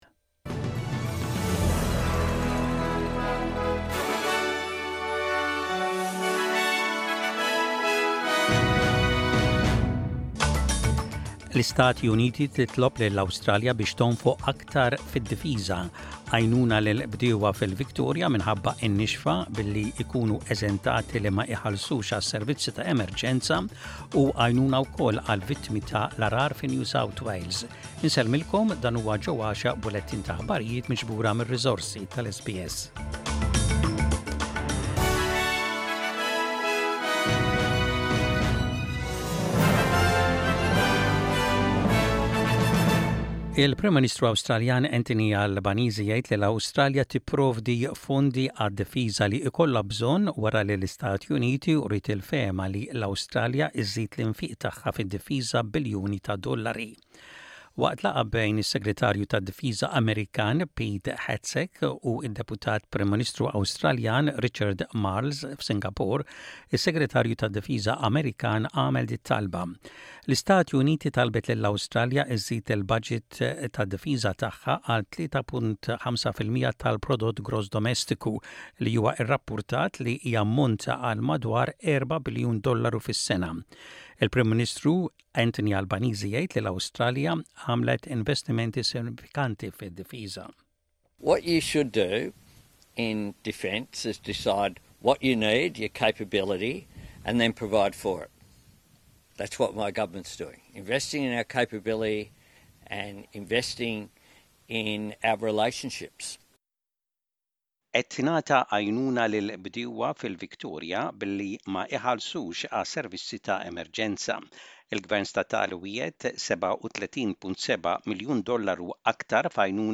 Aħbarijiet bil-Malti: 03.06.25